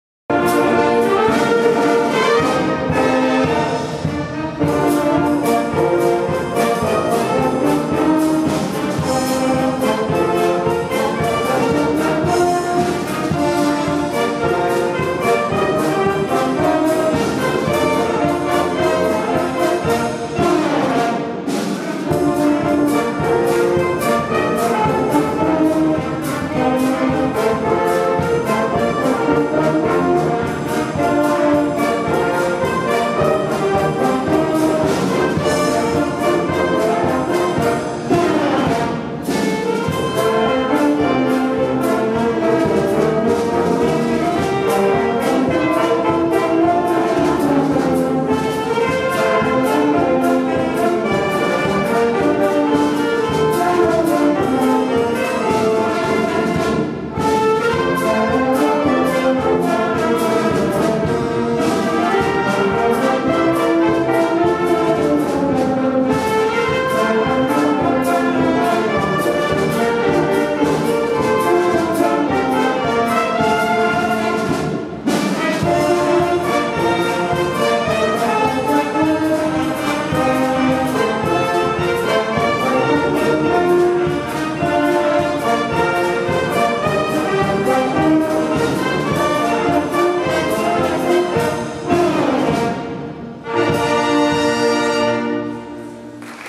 16 R Le Contrevenant L. VERMAELEN Pas redoublé 🖼